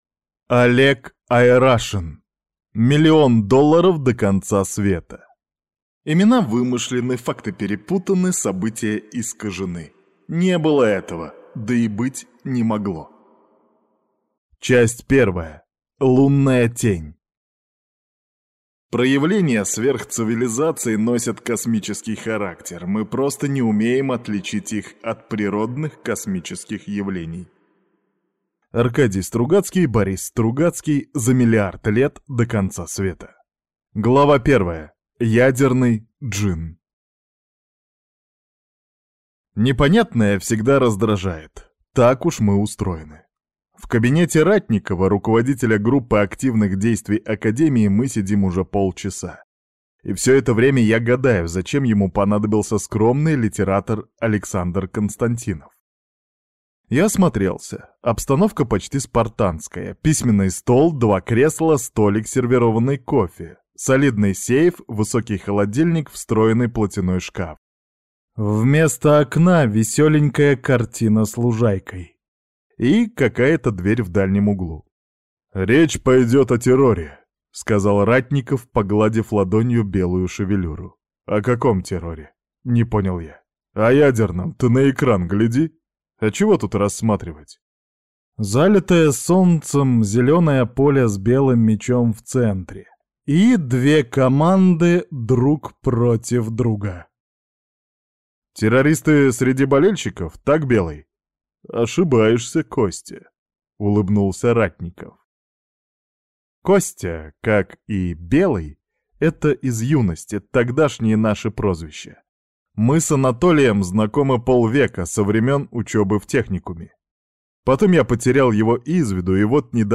Аудиокнига Миллион долларов до конца света | Библиотека аудиокниг
Прослушать и бесплатно скачать фрагмент аудиокниги